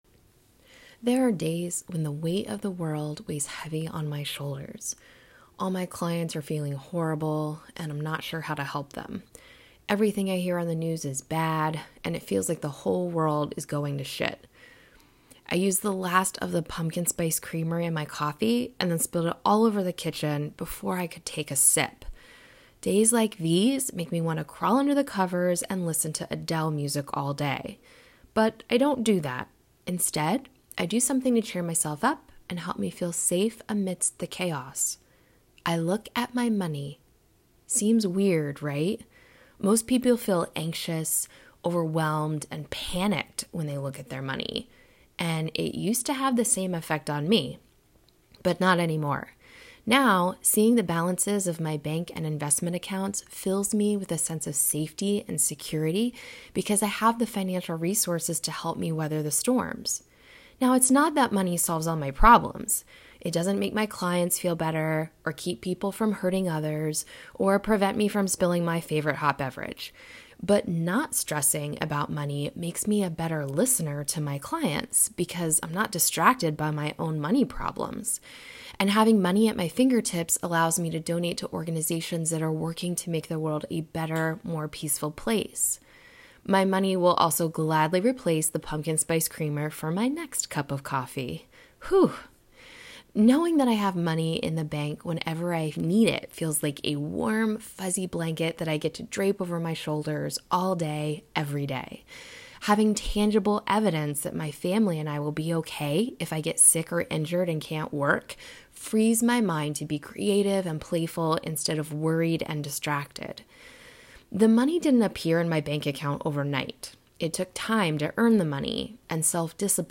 Press play and I’ll read this juicy article to you!